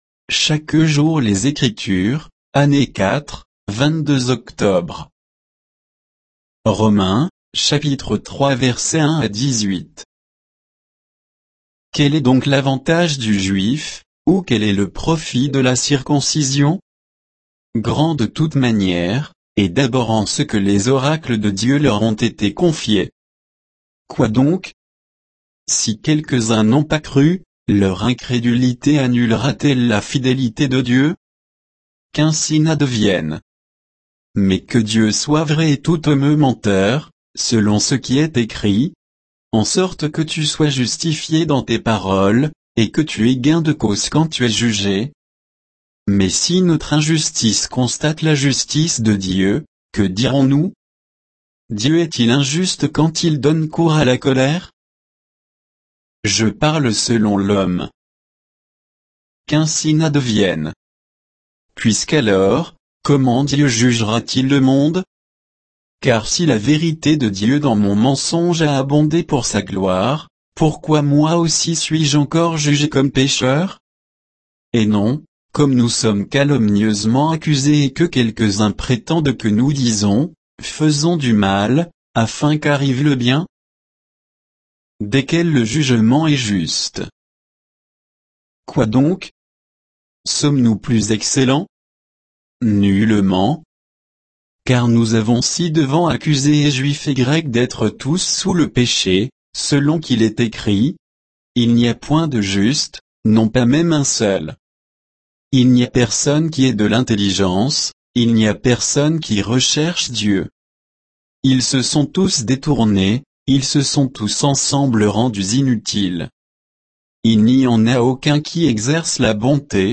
Méditation quoditienne de Chaque jour les Écritures sur Romains 3